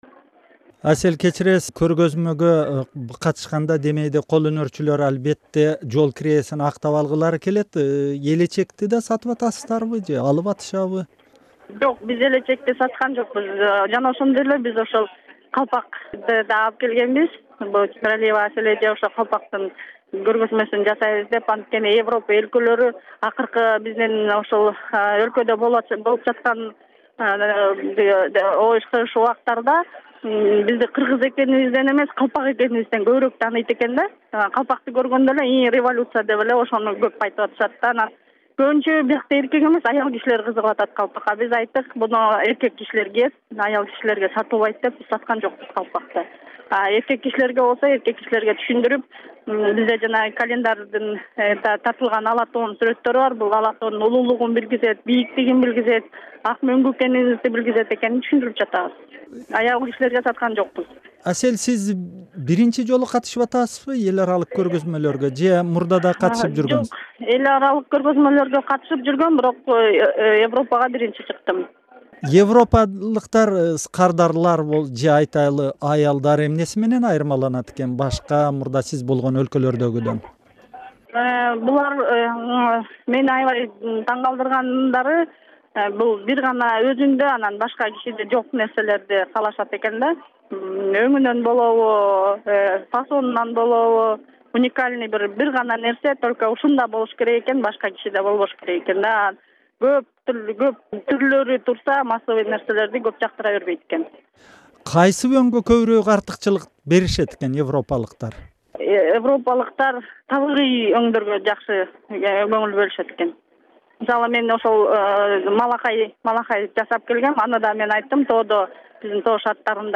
Эл аралык Import Shop Berlin көргөмө-жарманкесине катышкан кыргызстандык уз айымдар менен маек- 2
Germany, Import Shop Berlin Market, Handcrafters from Kyrgyzstan, Nov 11, 2011